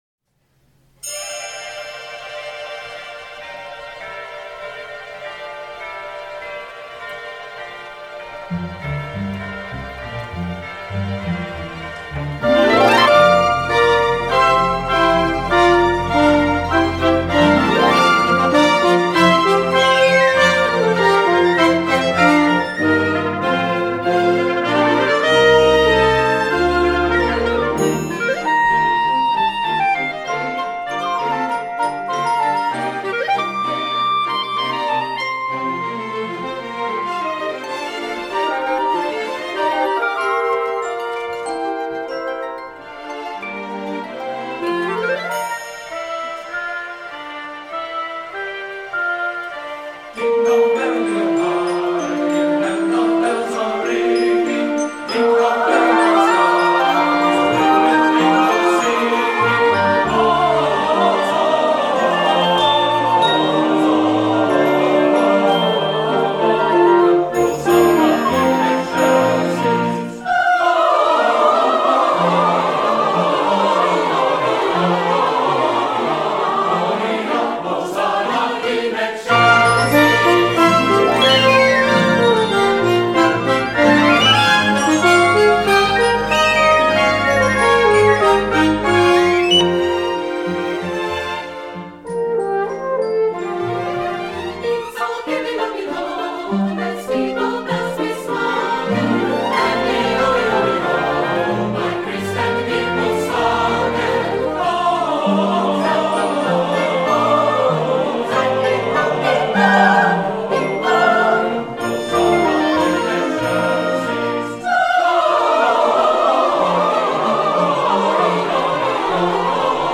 SOUND CLIP (of the chamber orch. version)